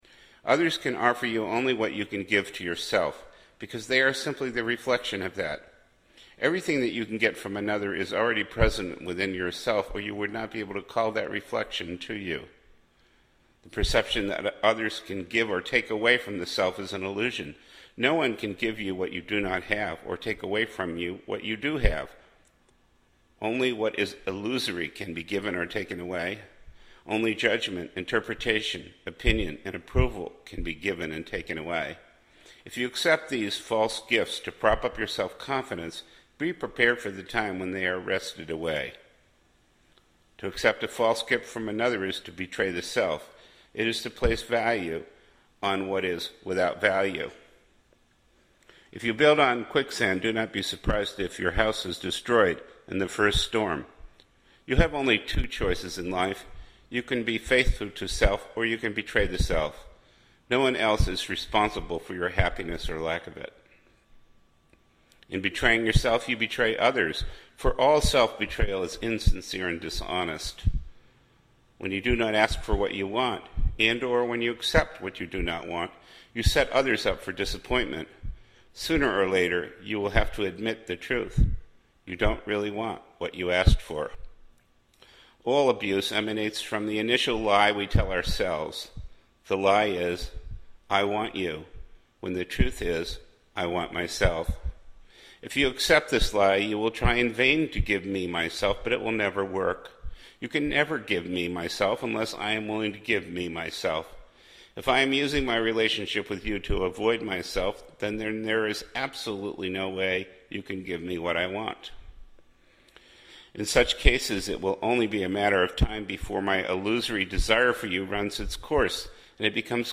reading from his Christ Mind titles.